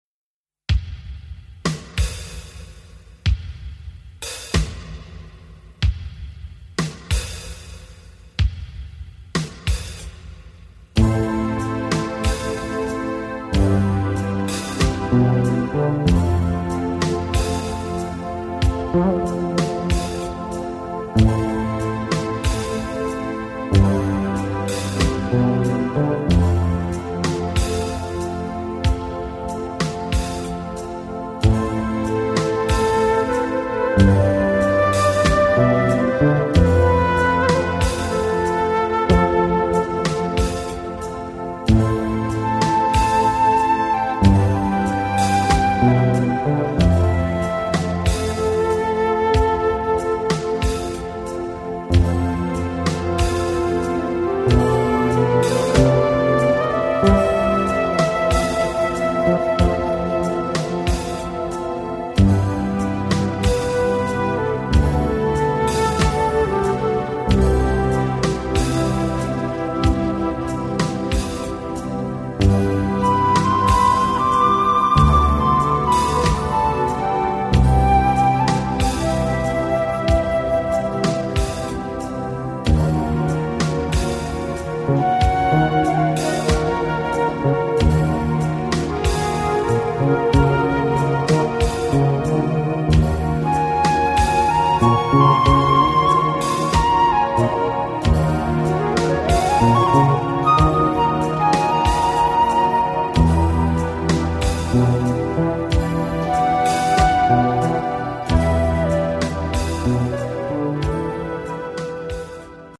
Often ethereal